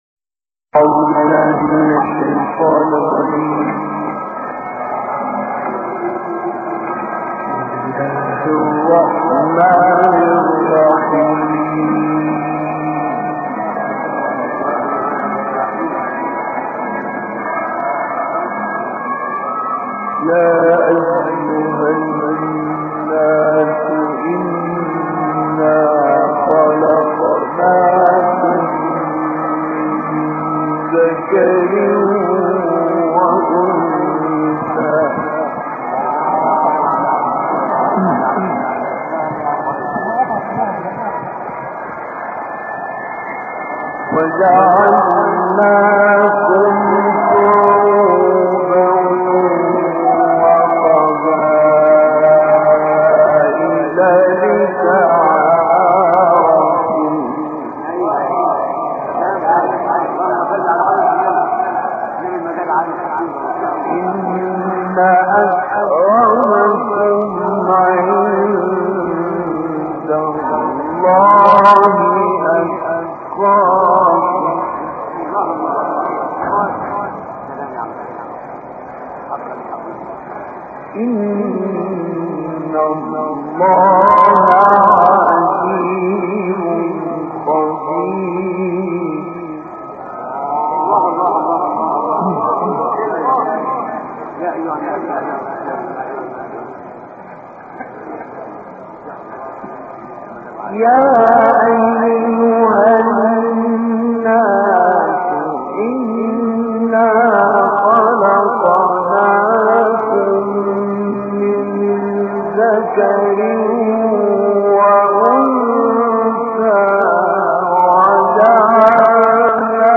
تلاوت دو ساعته «مصطفی اسماعیل» در مسجد سلطان ابوالعلاء
گروه فعالیت‌های قرآنی: تلاوت شاهکار سوره حجرات و ق با صوت مصطفی اسماعیل که در سال 1975 میلادی در مسجد معروف سلطان ابوالعلاء قاهره اجرا شده ارائه می‌شود.